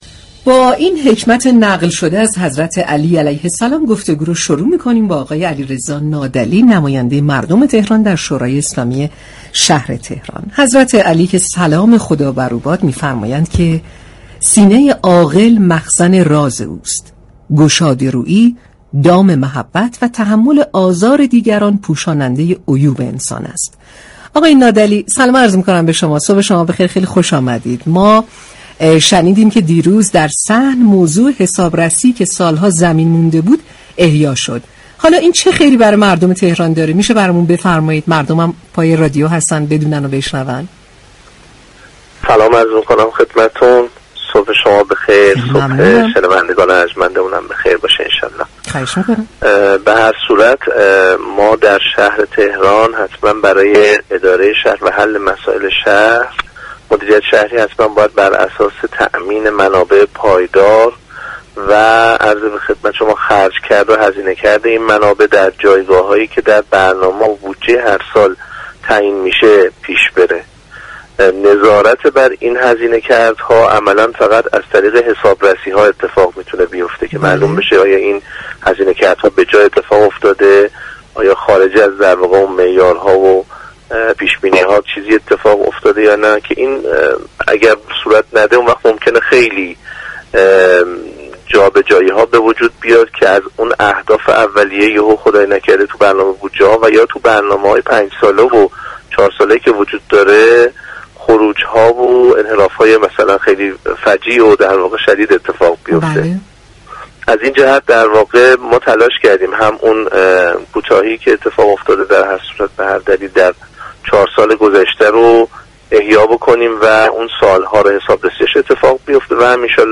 به گزارش پایگاه اطلاع رسانی رادیو تهران،علیرضا نادعلی سخنگو و نایب رئیس كمیسیون فرهنگی شورای اسلامی شهر تهران در گفت‌و‌گو با شهر آفتاب رادیو تهران درخصوص حسابرسی مربوط به سال‌های 1396 تا 1399 شهرداری تهران كه از روز گذشته 28 تیرماه در صحن علنی شورای اسلامی شهر تهران آغاز شده است، گفت: بر اساس بند 30 ماده 80 قانون تشكیلات وظایف و انتخابات شورا‌های اسلامی كشور، نظارت بر حسن اداره امور مالی شهرداری و همینطور سازمان‌ها و موسسه‌ها و شركت‌های وابسته و نظارت بر حساب درآمد هزینه آن‌ها با انتخاب حسابرس رسمی بر عهده شورای شهر است.